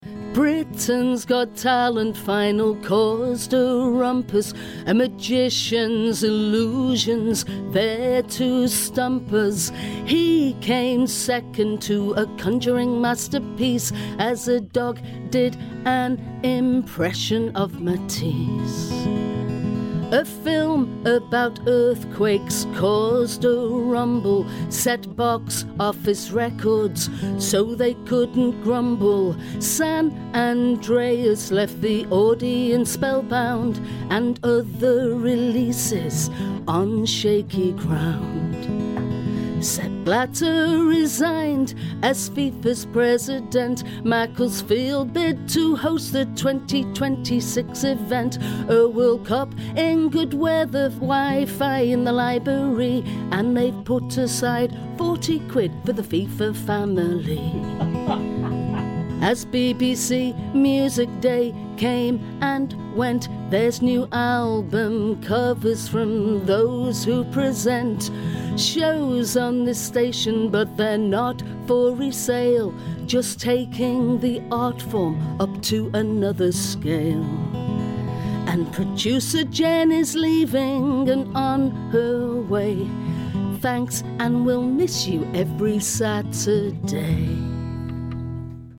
The week's news in song